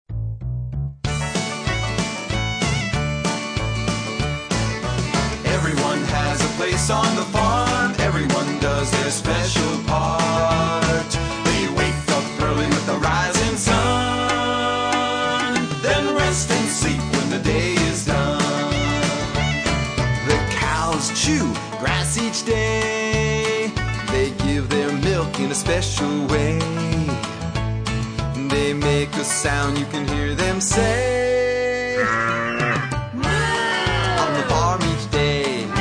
We wanted children to hear the real animal sounds.